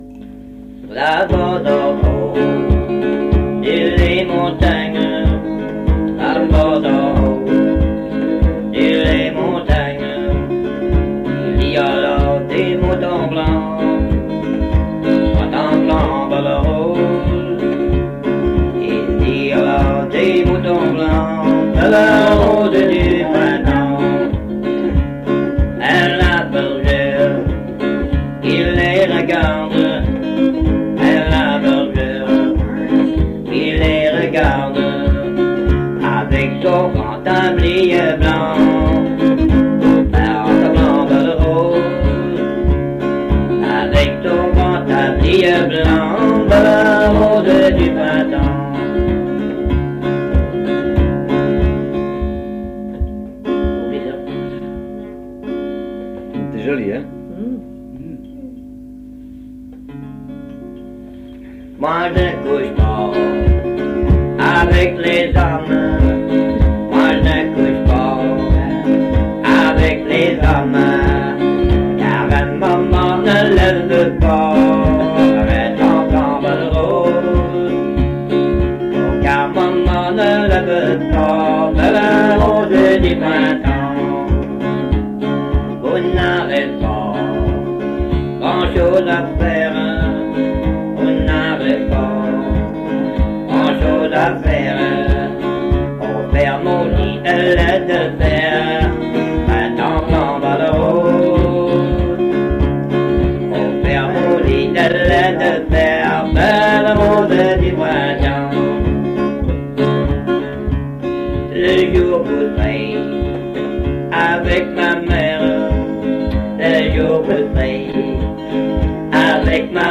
Chanson Item Type Metadata
Avec guitare